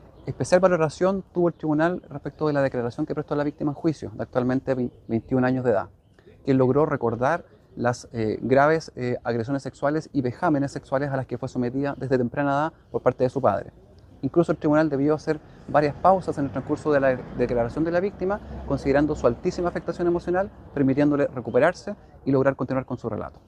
El fiscal jefe del Ministerio Público en Angol, Cristián Gacitúa, precisó que el Tribunal valoró especialmente la declaración que prestó la víctima en el juicio, de 21 años en la actualidad.